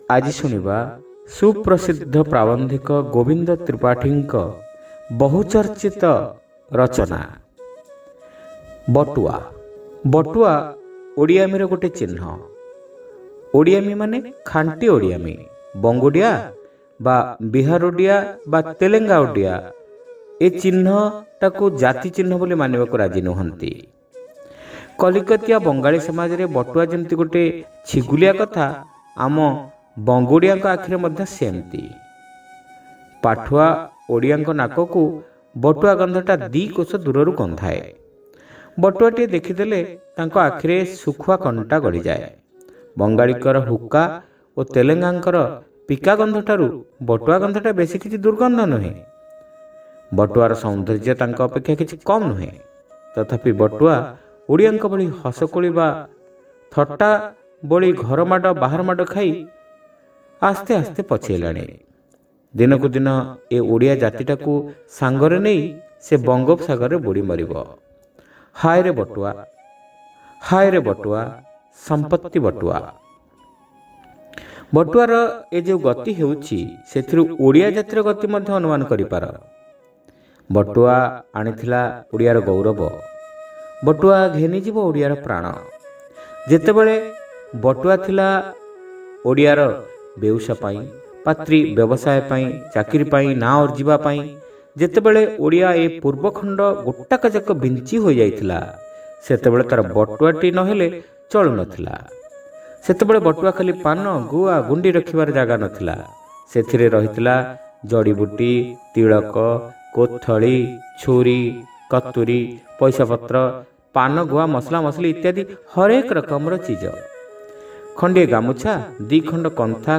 ଶ୍ରାବ୍ୟ ଗଳ୍ପ : ବଟୁଆ (ପ୍ରଥମ ଭାଗ)